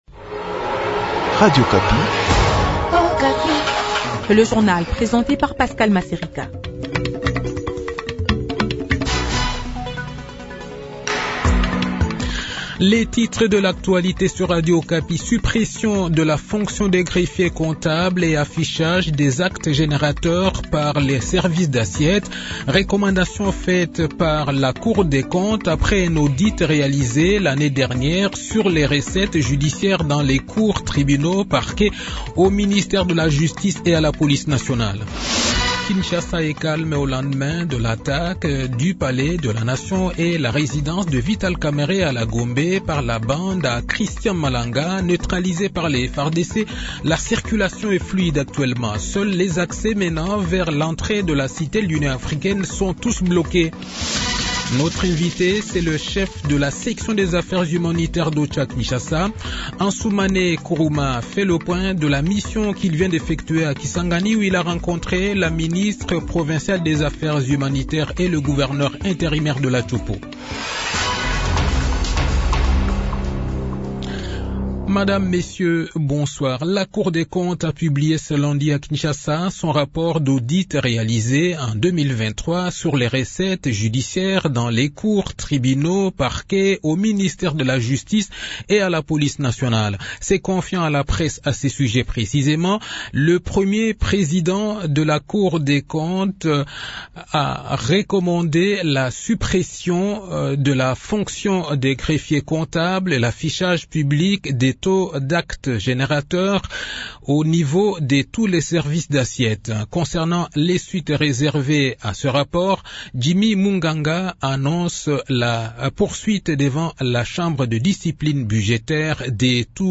Le journal de 18 h, 20 mai 2024